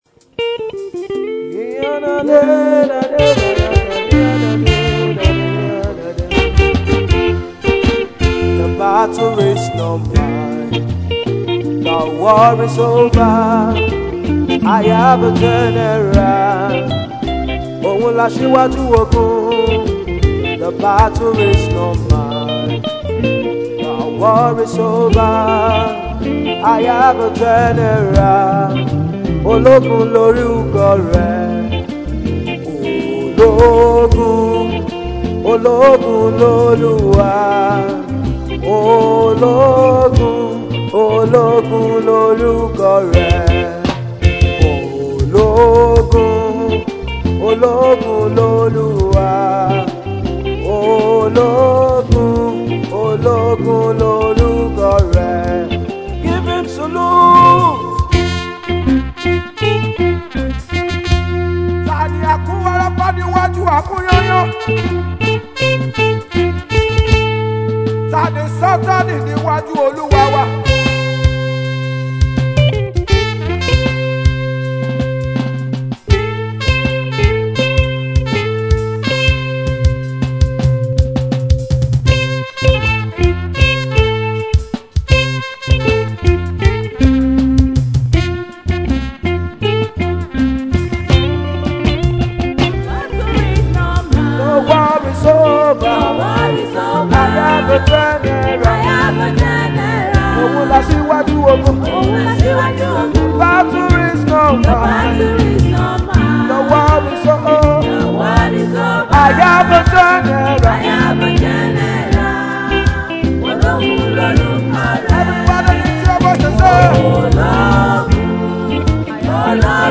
single
gospel singer